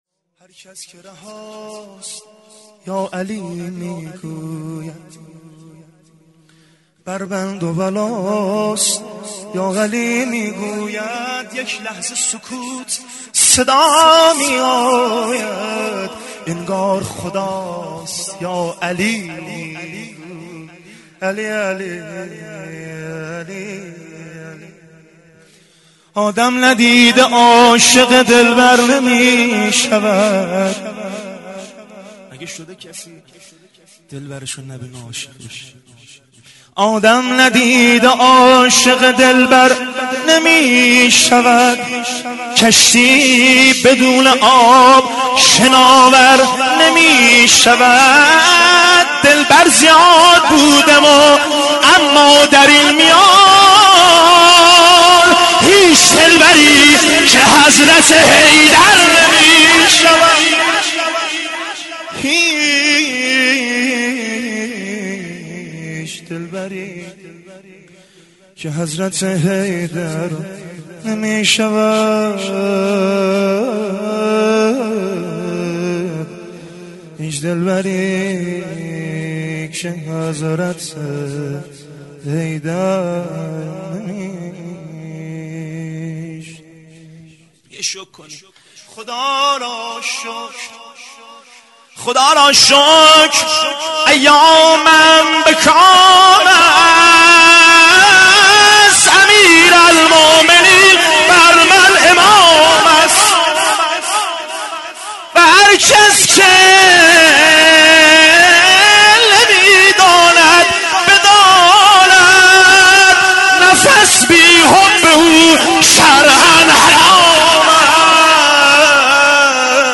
مدح فوق العاده زیبا در رثای مولا جان امیرالمومنین علی علیه السلام